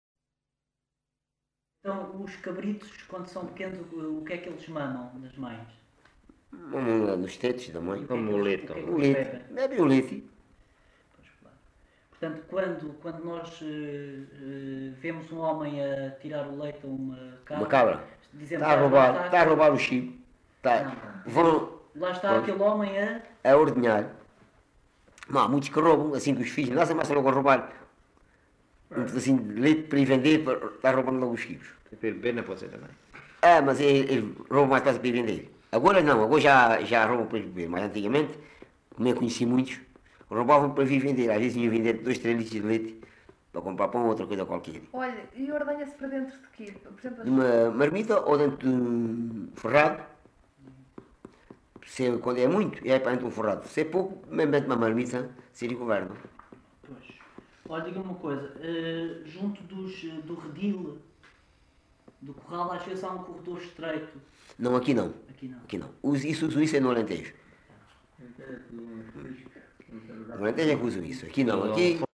LocalidadeAlte (Loulé, Faro)